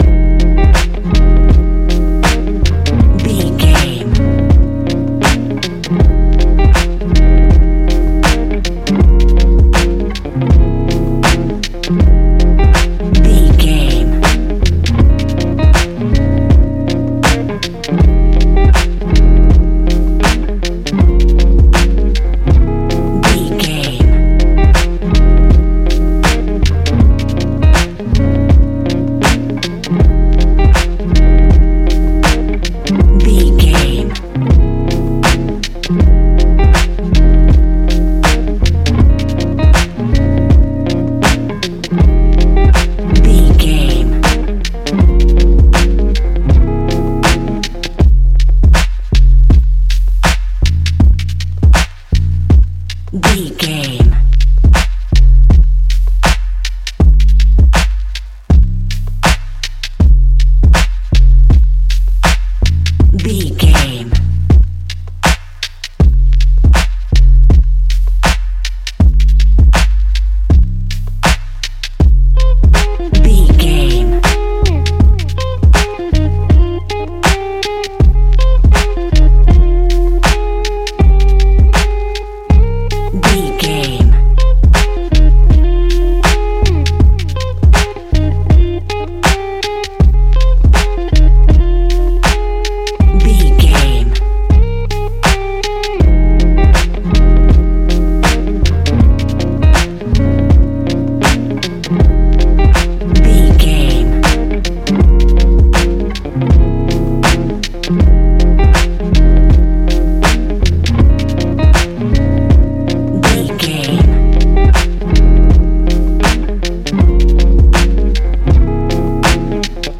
Ionian/Major
F♯
chilled
laid back
Lounge
sparse
new age
chilled electronica
ambient
atmospheric